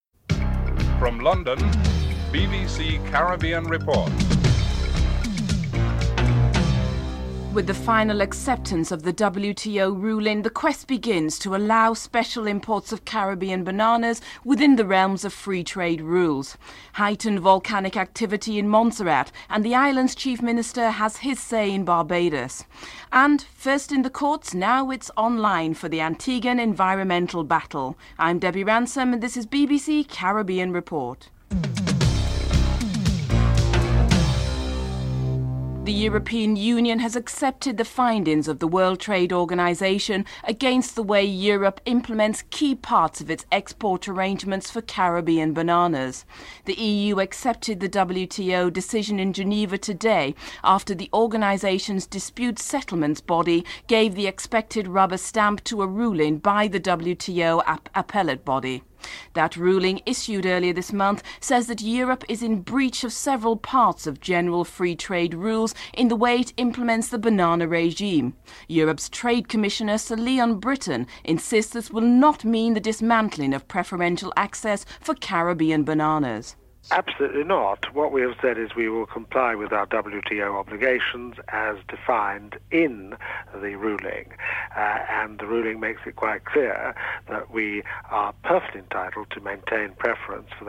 1. Headlines (00:00-00:34)
Sir Leon Brittan, Europe's Trade Commissioner, Edwin Laurent, OECS Ambassador to Brussels, Edison James, Prime Minister of Dominica are interviewed.